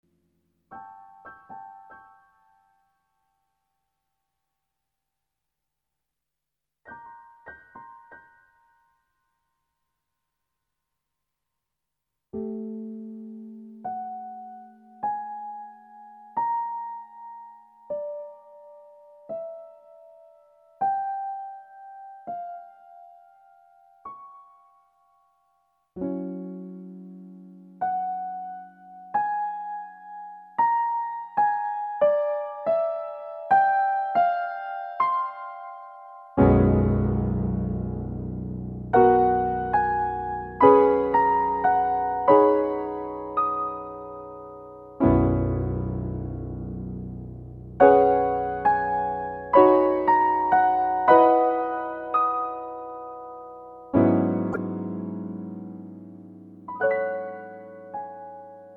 piano solos